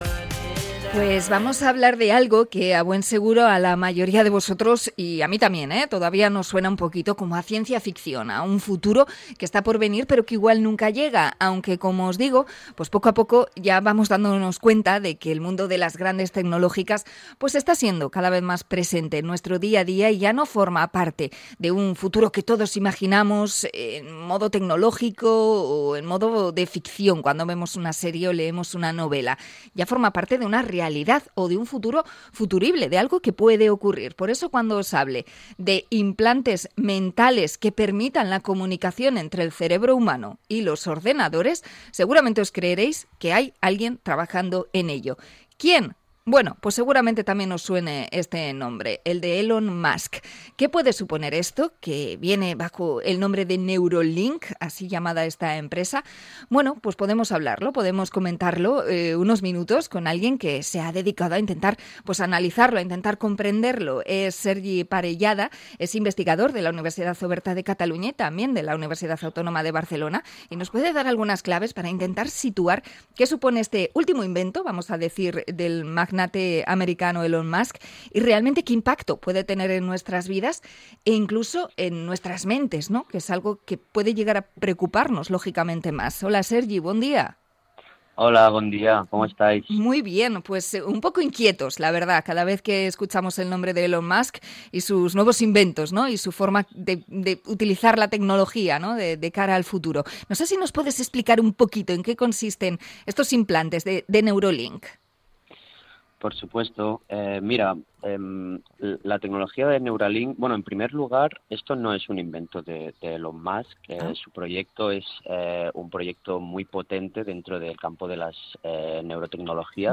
Entrevista a investigador sobre el control mental y los chips mentales de Elon Musk Neuralink